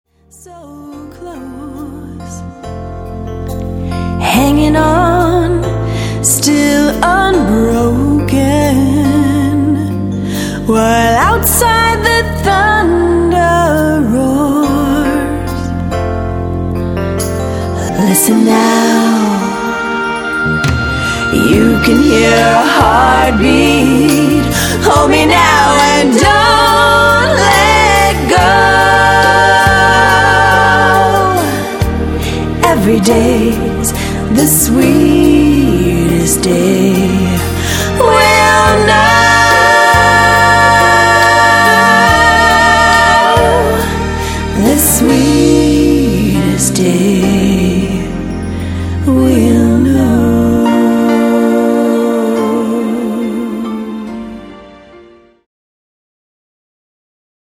VOCAL DEMO
pop/R&B ballad